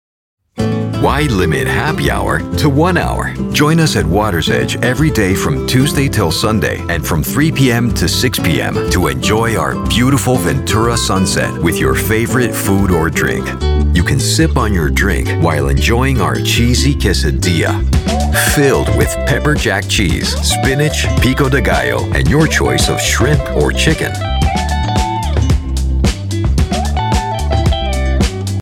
Deep, Conversational, Natural Male Voice Over Talent
Male
Adult (30-50), Older Sound (50+)
Radio Commercials
Restaurant Happy Hour Promo
1109WatersEdgeHappyHourTrio-Quesadilla_NEW_.mp3